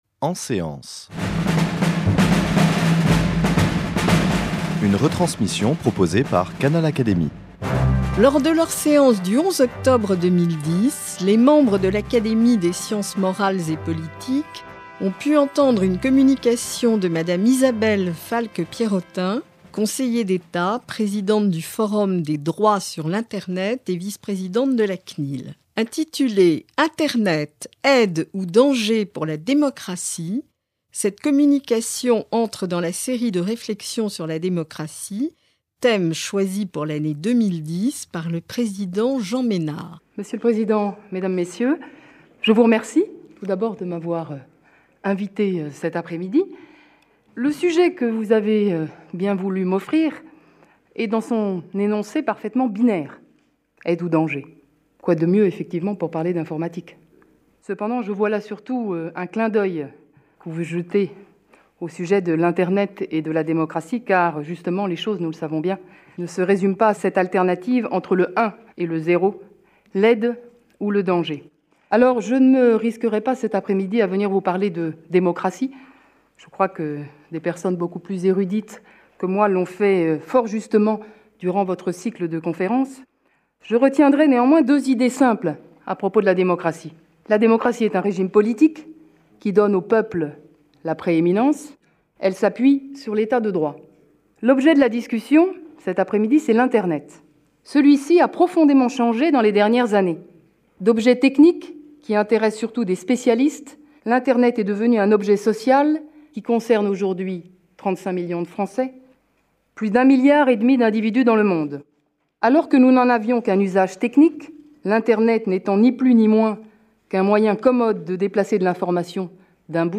Dans une communication prononcée le 11 octobre 2010 devant l’Académie des Sciences Morales et Politiques, retransmise par Canal Académie, Isabelle Falque-Pierrotin, Conseiller d’Etat, Présidente du Forum des droits sur l’Internet, Vice-présidente de la CNIL, nous invite à une réflexion sur ces questions essentielles pour l’avenir des démocraties.